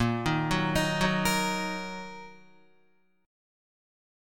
Bbsus4#5 chord